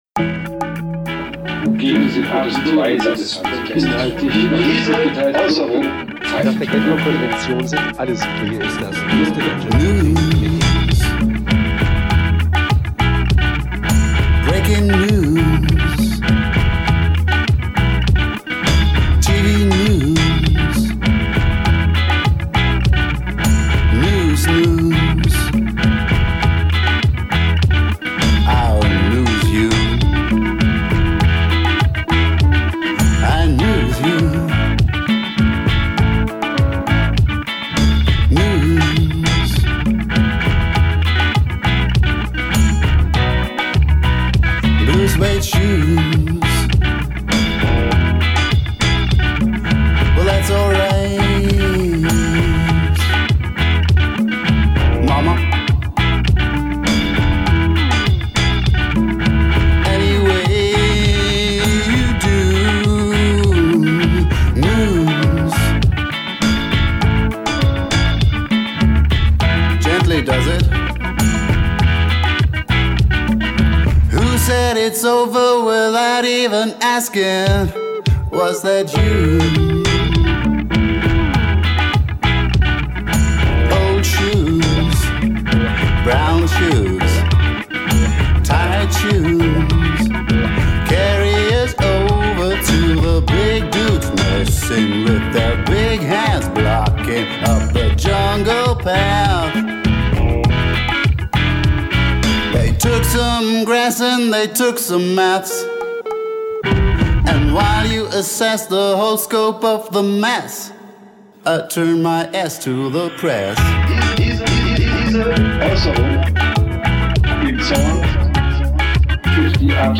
Rock'n'Roll, d + b + 2g + voc + quotes